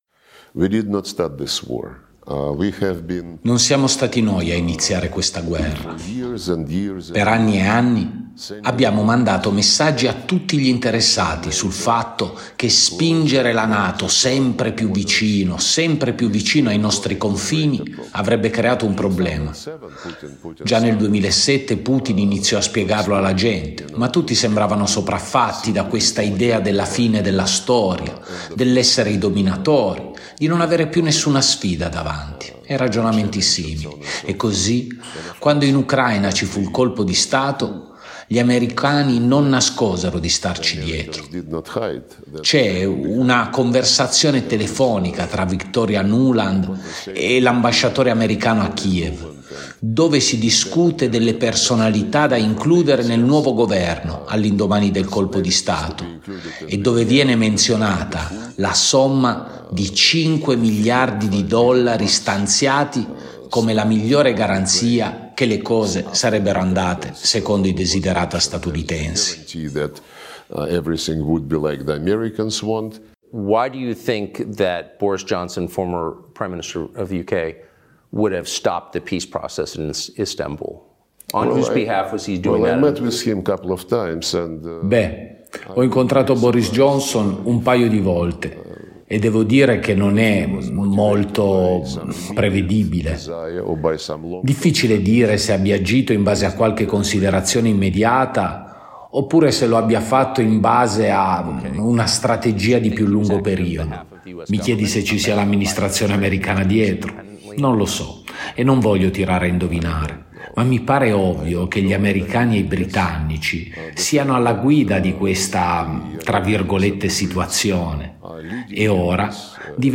Ascolta la nostra selezione dell’intervista a Sergej Lavrov
Vi proponiamo alcuni estratti dell’intervista al ministro degli esteri russo Sergej Lavrov, pubblicata sul canale di Tucker Carlson, eclettico giornalista già fuoriuscito da Fox News e oggi vicino al neo presidente Trump. Lavrov parla nel suo stile molto flemmatico di temi a dir poco bollenti, dalla situazione della Siria, a pochi giorni dal vertice di Doha, alla possibilità concreta di una escalation atomica fino alla situazione molto dinamica dell’Indo Pacifico, dove gli americani concretizzano alleanze e cercano di coinvolgere la Nato che ormai non ha problemi ad ammettere il proprio interesse per quel quadrante geopolitico.